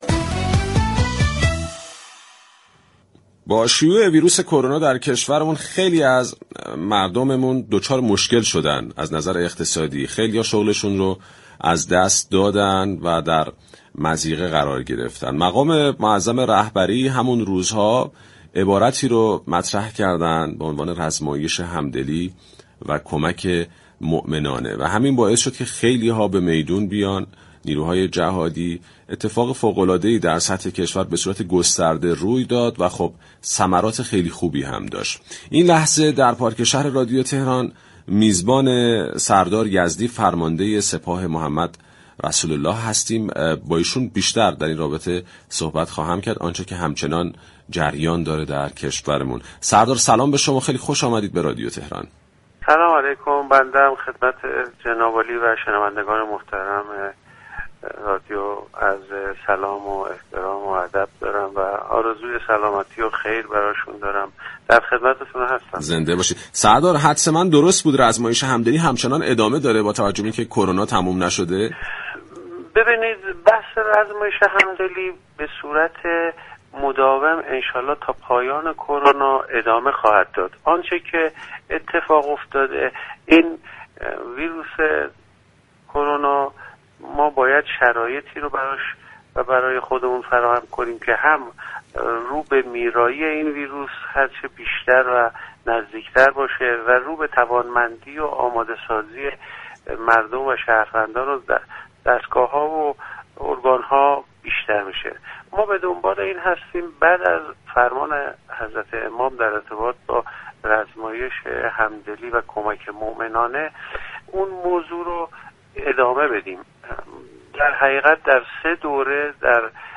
سردار محمدرضا یزدی در گفتگوی تلفنی با "پارك‌شهر" رادیو تهران در روز 22 خردادماه اظهار داشت: آنچه كه این روزها در كشورمان ضروری است فراهم شدن شرایطی است تا ویروس كووید 19 را به سوی میرایی برده و مردم نیازمند در شهرها و روستاها كه به واسطه‌ی حضور این ویروس دچار مشكلات عدیده شده‌اند مورد حمایت اقتصادی قرار بگیرند.